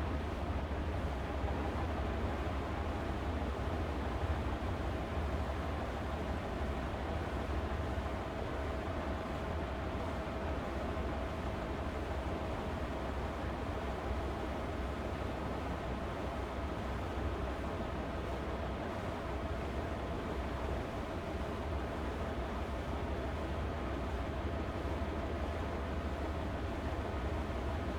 sol_reklam_link sag_reklam_link Warrock Oyun Dosyalar� Ana Sayfa > Data > Sound > Boat > HAMINA Dosya Ad� Boyutu Son D�zenleme ..
WR_ENGINE.wav